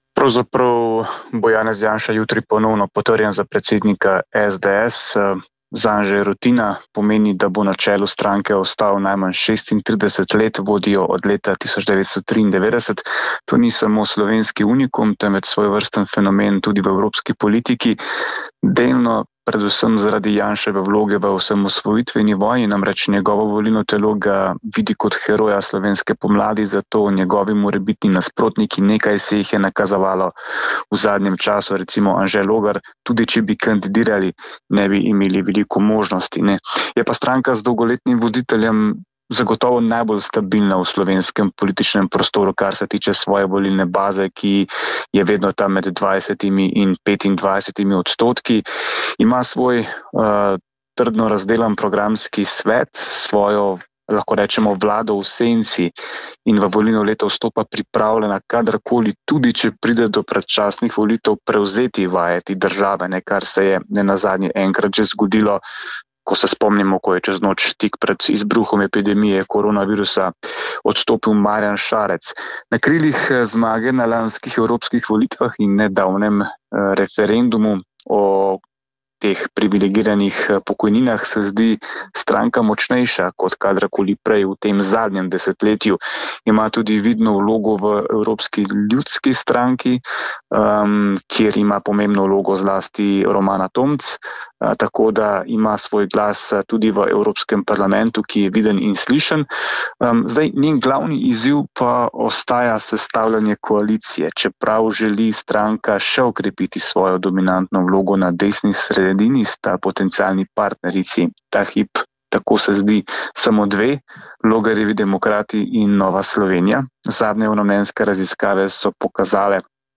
pričevanje